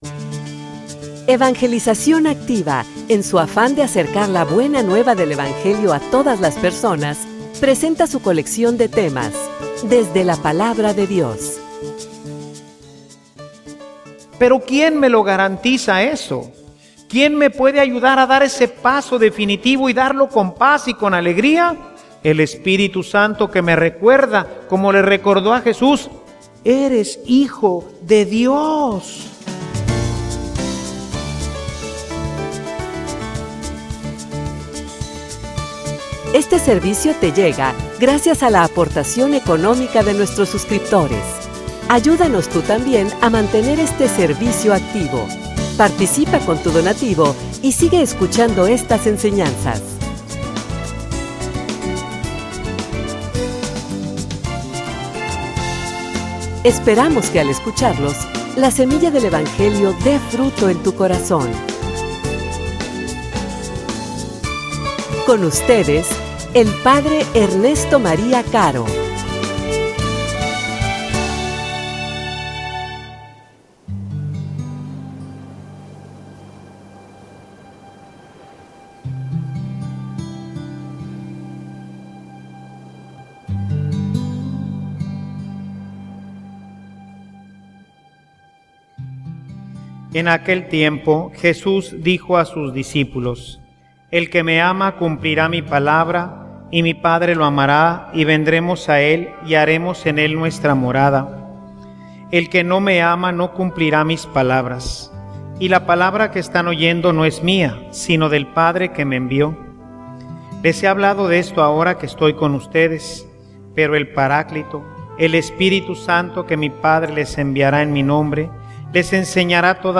homilia_La_confianza_que_nos_lleva_a_la_paz.mp3